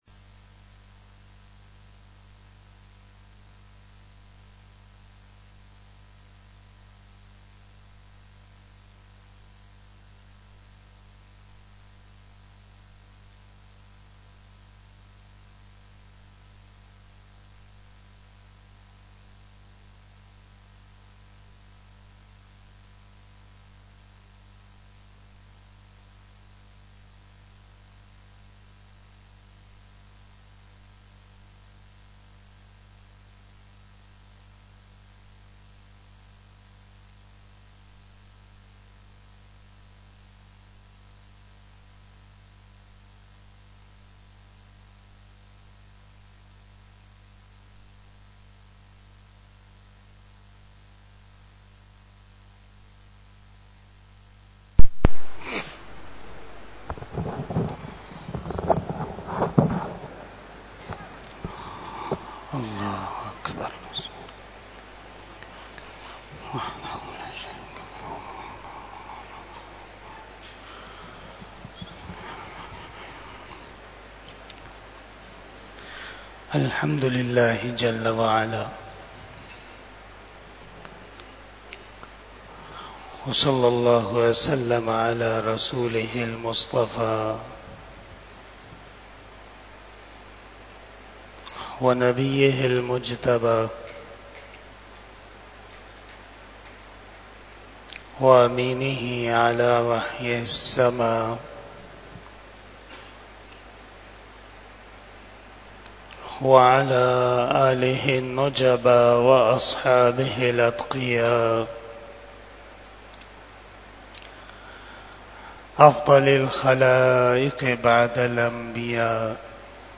بیان جمعۃ المبارک 22 ربیع الاول 1446ھ بمطابق 27 ستمبر 2024ء